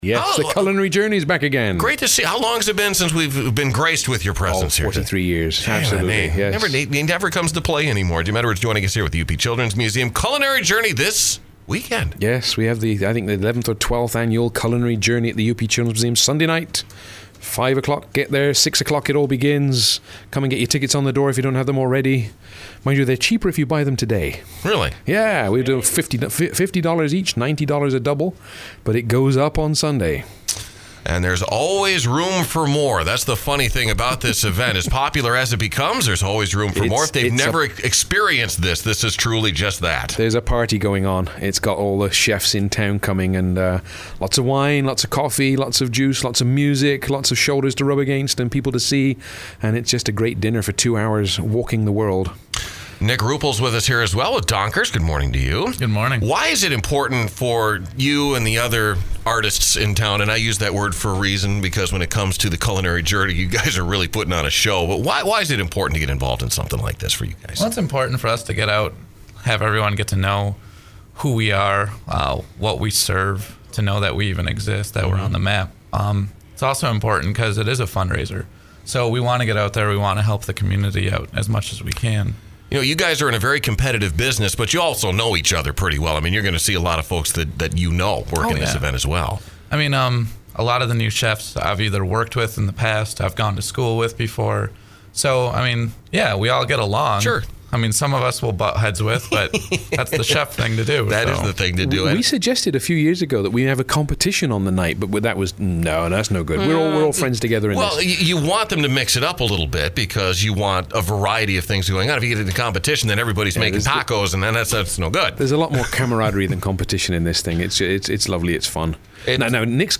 UP News